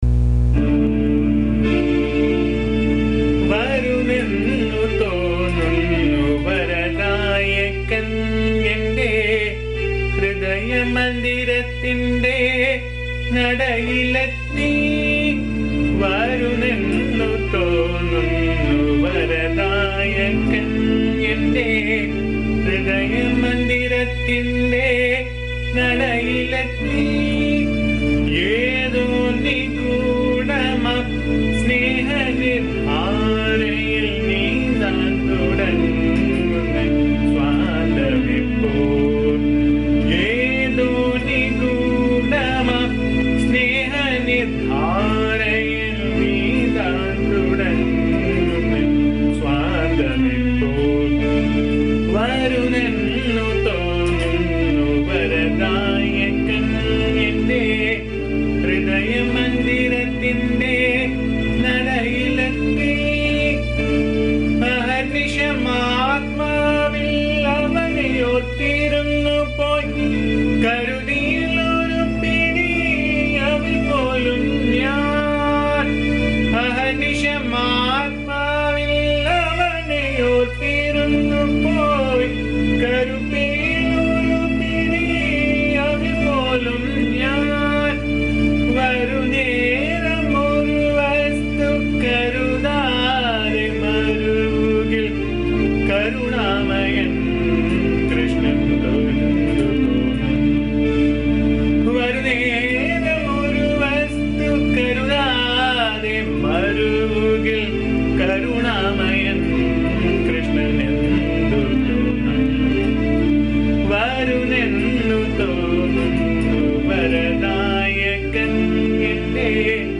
This is a very beautiful song set in the Raga Kharaharapriya. This is a first-person perspective of the mental state of a devotee who has been awaiting Lord Krishna in his heart and when Krishna arrives in the heart of the devotee.
The song sung in my voice can be found here.
AMMA's bhajan song